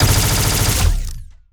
Plasmid Machinegun
GUNAuto_Plasmid Machinegun Burst_02_SFRMS_SCIWPNS.wav